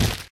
Hit.ogg